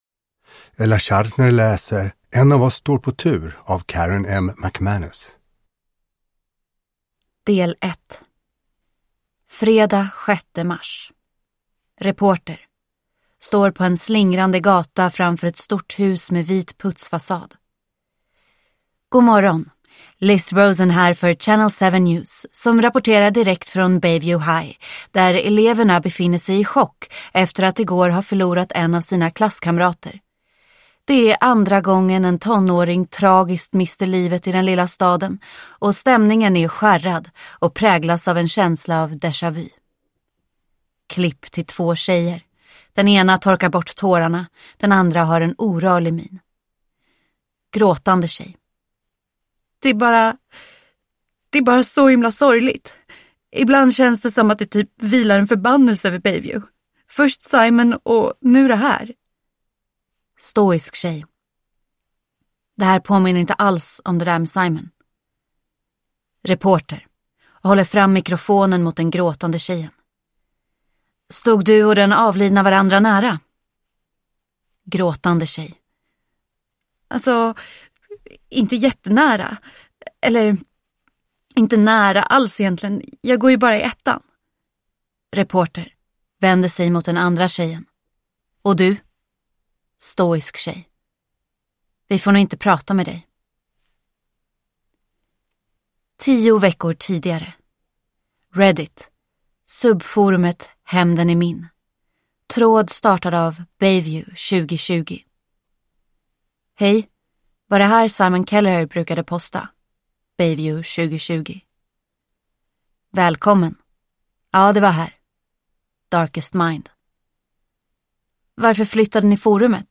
En av oss står på tur – Ljudbok – Laddas ner